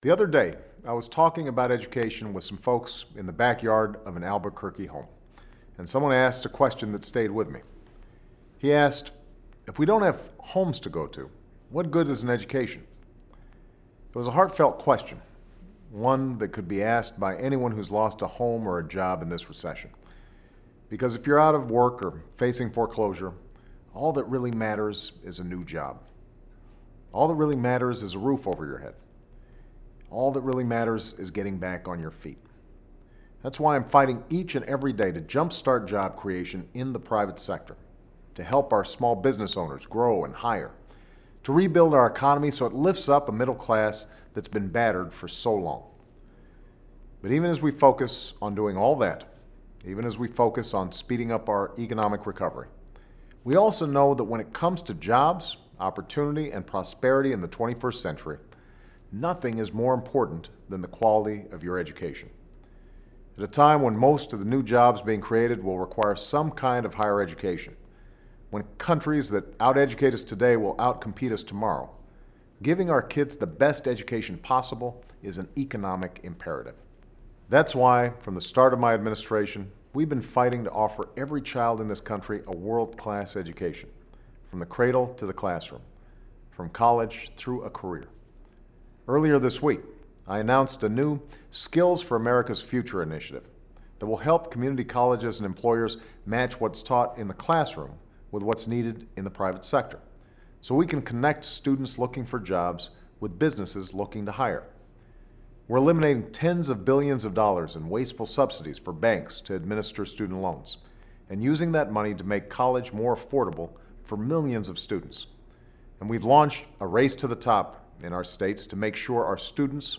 Barack Obama's weekly radio addresses (and some other speeches).